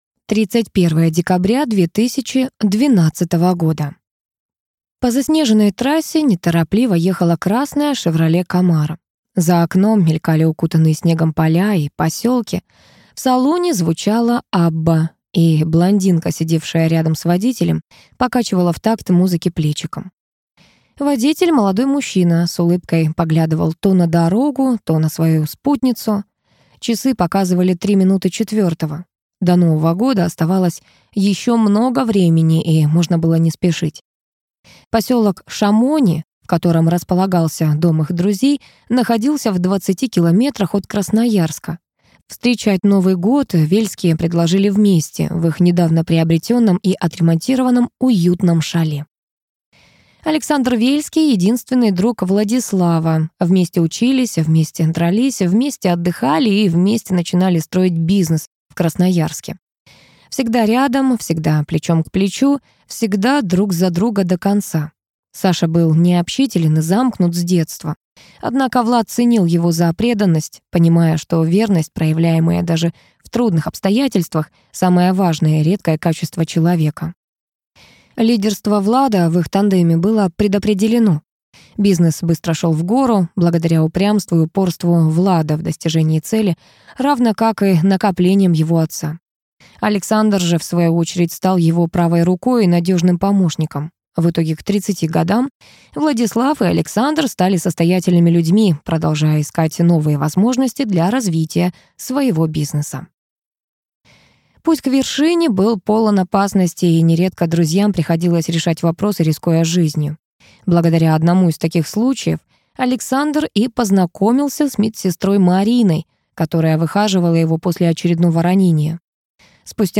Аудиокнига Щит любви | Библиотека аудиокниг
Прослушать и бесплатно скачать фрагмент аудиокниги